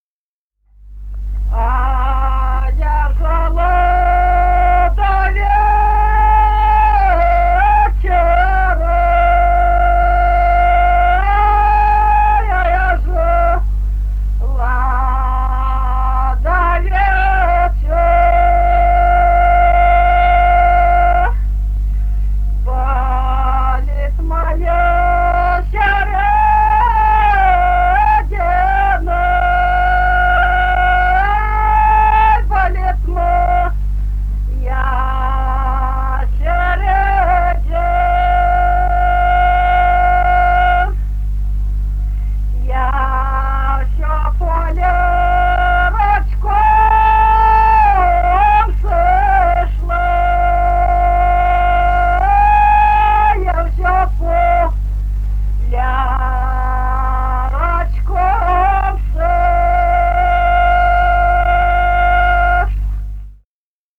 Русские народные песни Красноярского края.
«А я жала до вечора» (жнивная). с. Бражное Канского района.